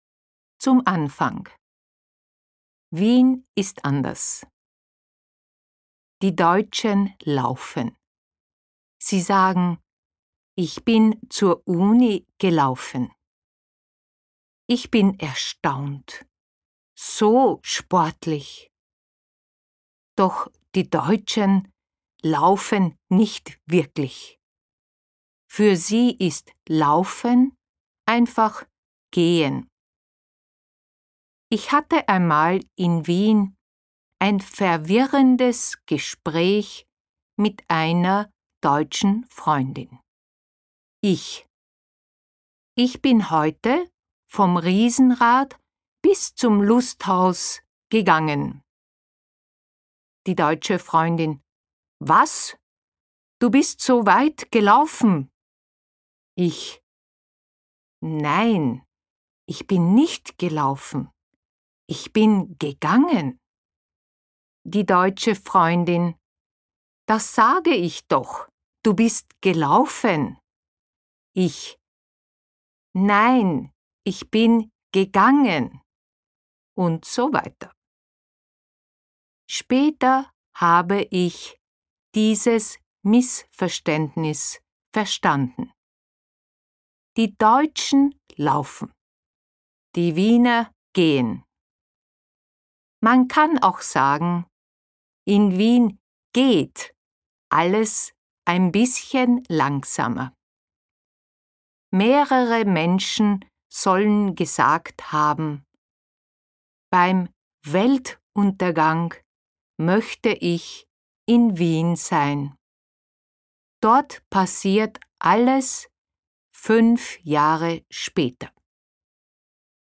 音声は，ウィーン出身のオーストリア人による心地よいドイツ語で収録されています。